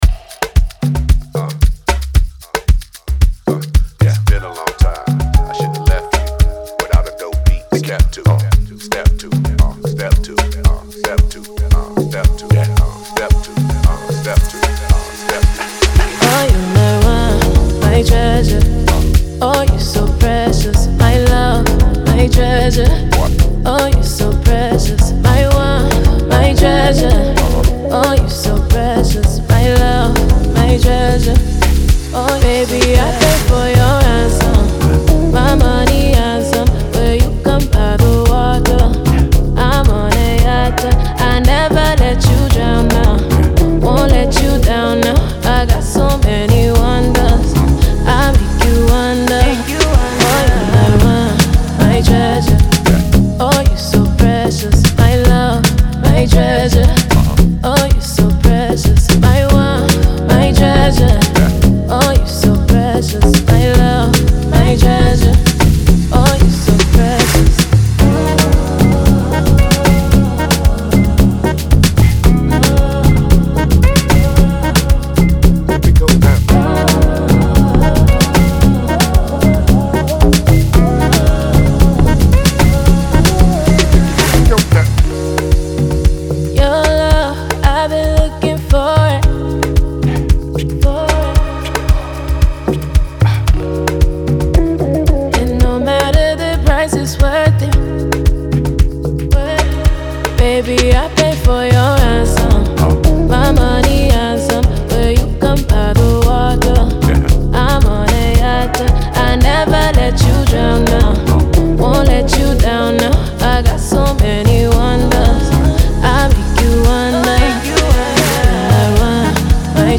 • Жанр: Pop, R&B